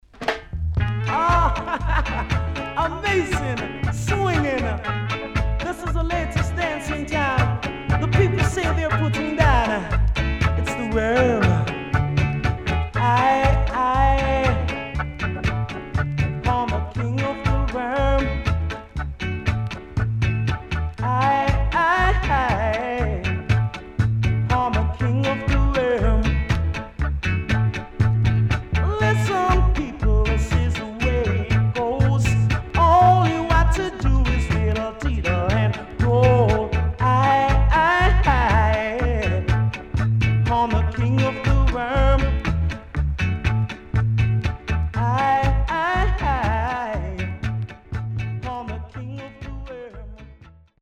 Good Vocal & Funky Organ Inst